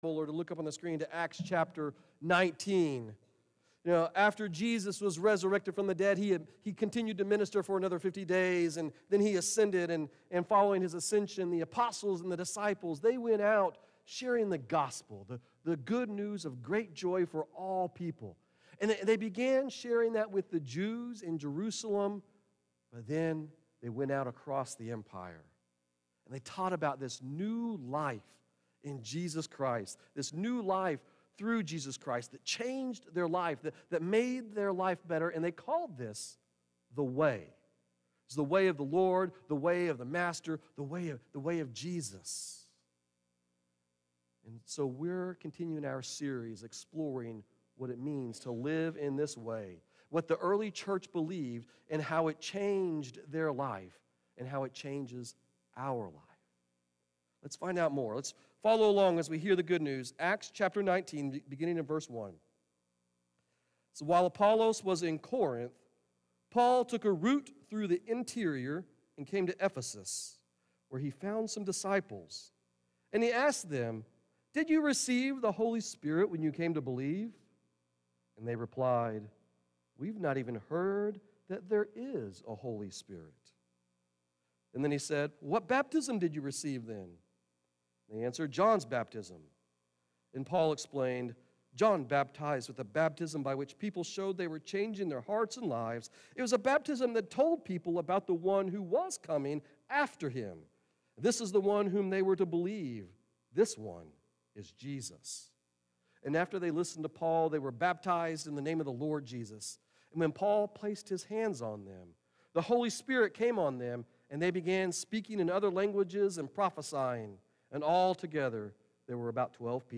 Sermons | First United Methodist Church
The Way John 3:5–8 Guest Speaker May 12, 2024 What is Communion?